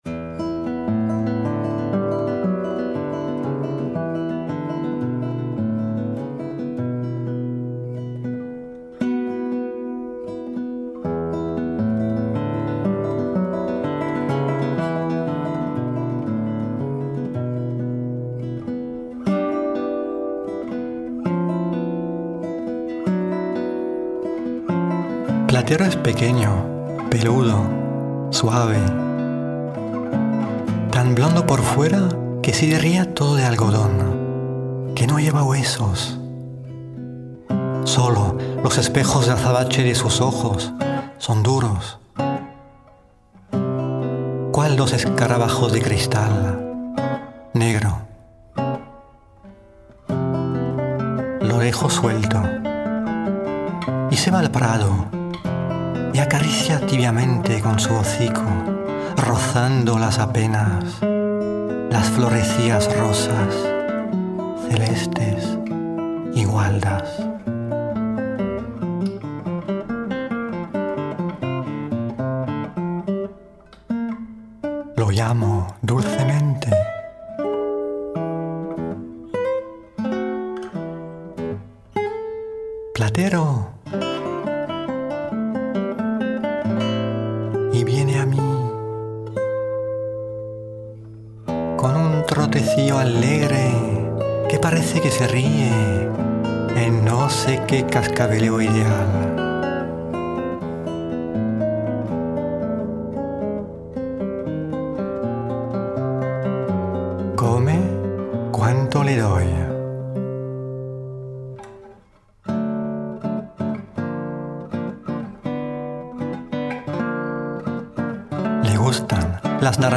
pour narrateur et guitare